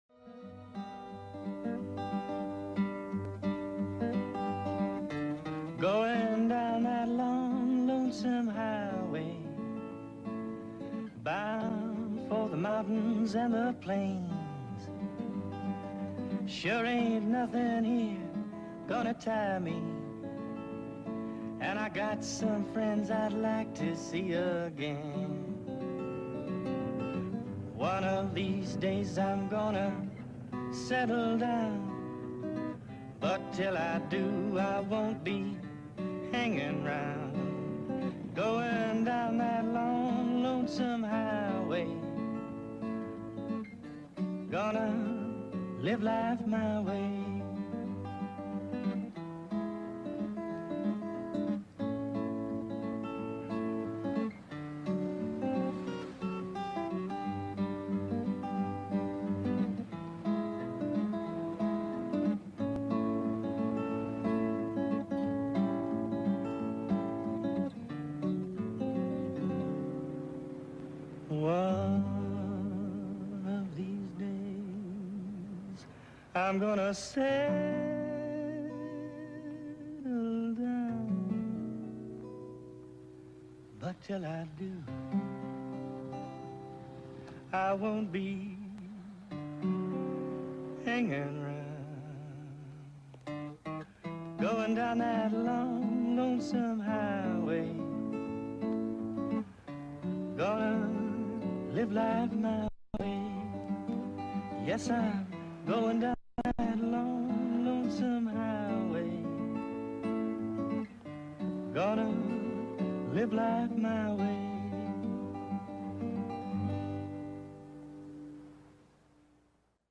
For some reason I had the theme song from '